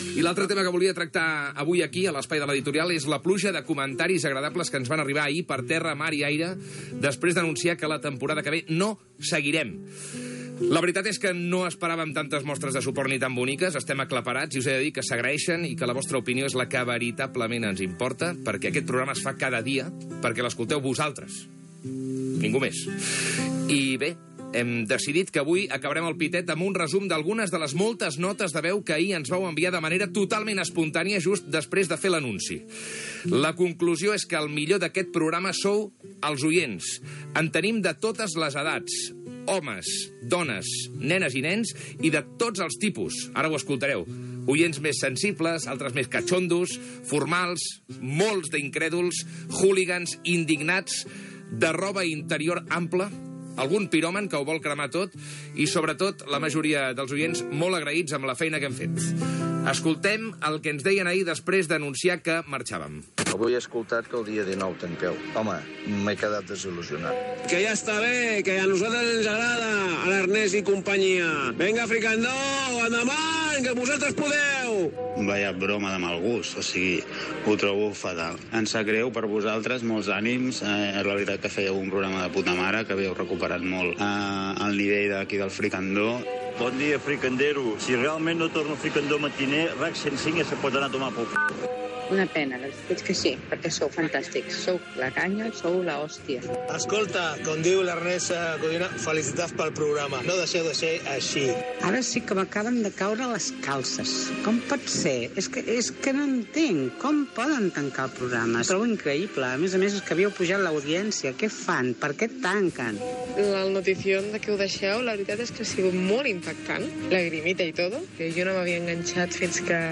Secció "El pitet": editorial d'agraïment als comentaris rebuts des que el dia anterior l'equip va anunciar que el programa no seguiria a RAC 105. Resum de trucades de l'audiència. Indicatiu del programa
Entreteniment